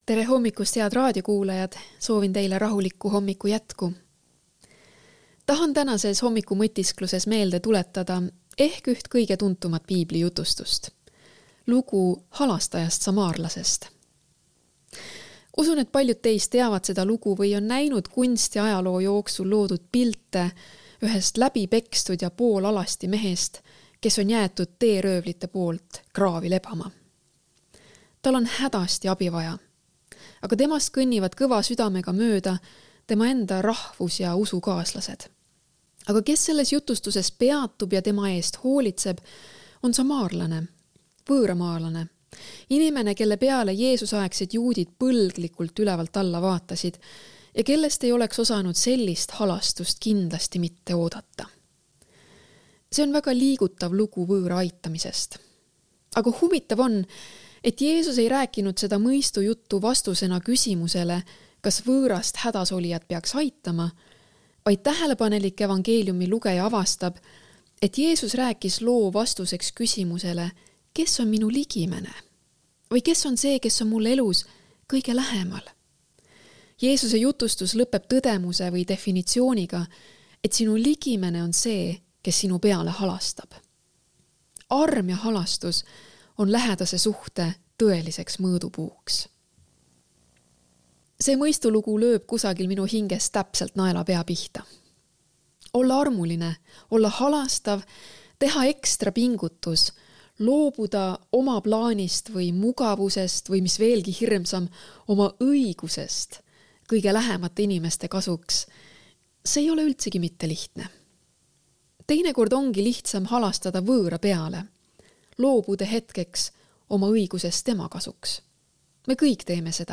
hommikupalvus ERR-is 25.06.2024